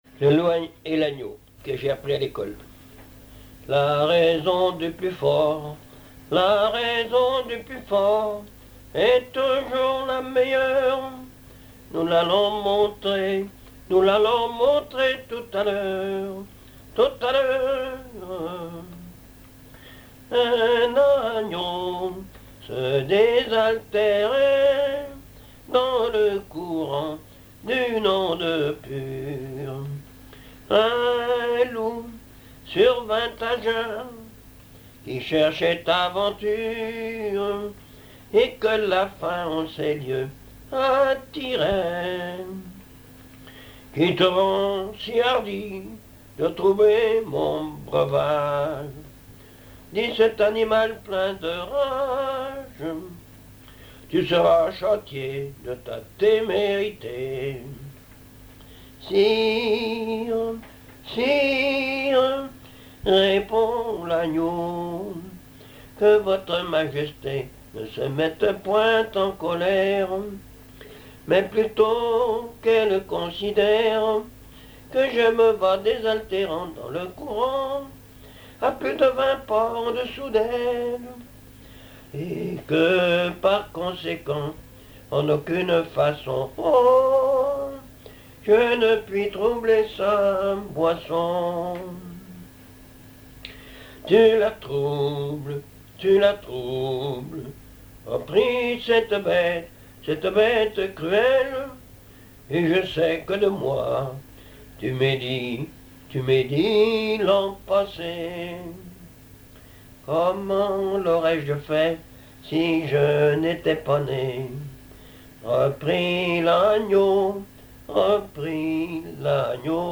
Mémoires et Patrimoines vivants - RaddO est une base de données d'archives iconographiques et sonores.
Genre strophique
chansons populaires
Pièce musicale inédite